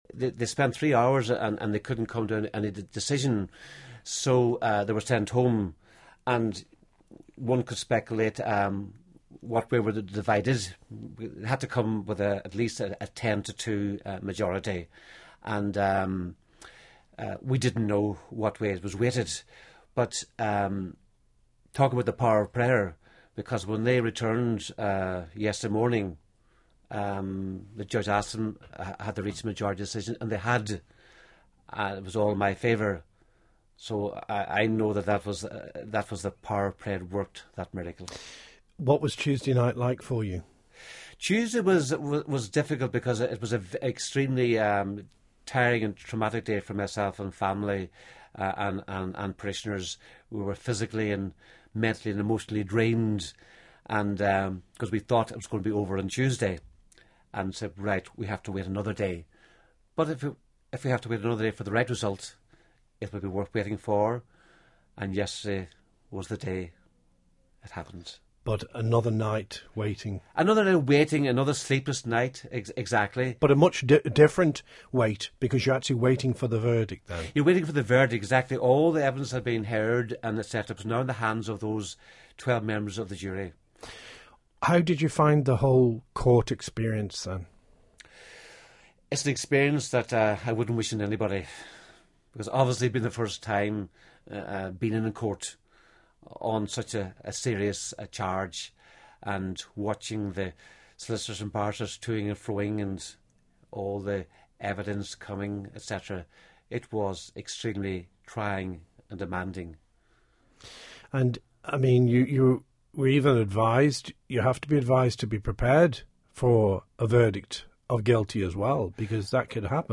a lengthy interview